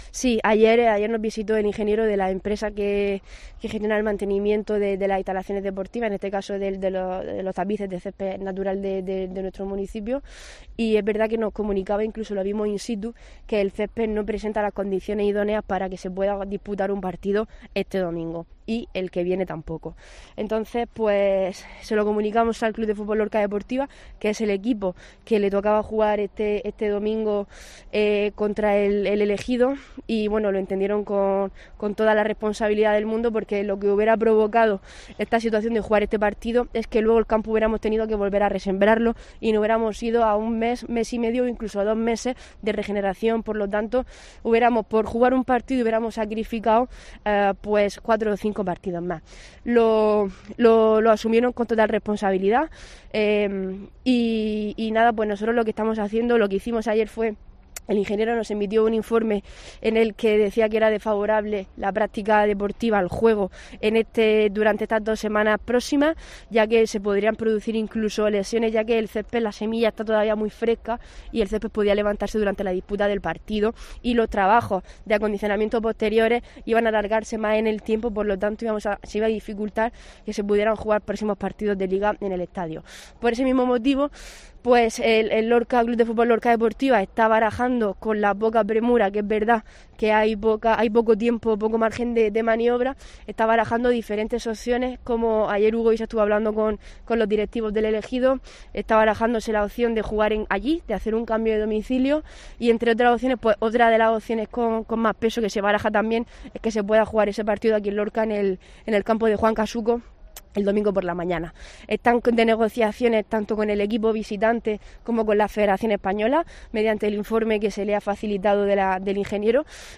Irene Jódar, edil de deportes sobre Artés Carrasco.